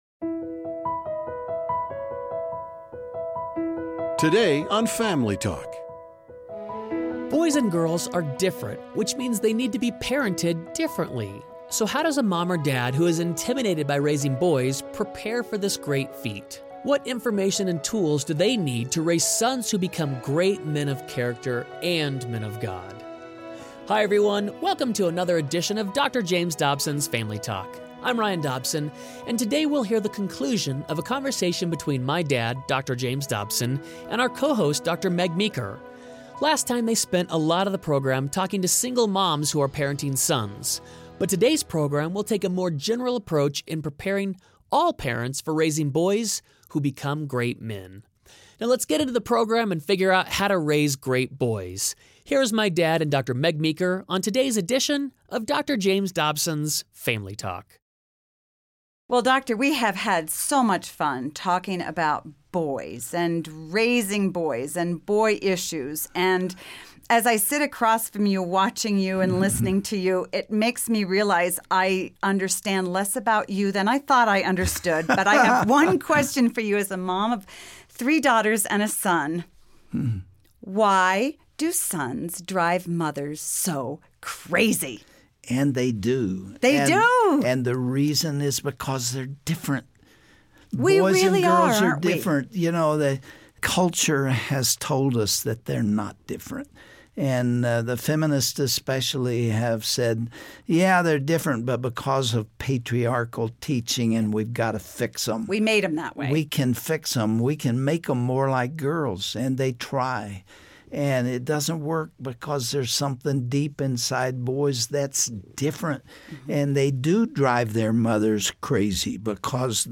Many people think that raising boys is difficult, but it can be pretty easy if you have the right tools. On today's braodcast, Dr. James Dobson and Dr. Meg Meeker— two of the world’s foremost experts on parenting—share how to raise boys to become great men.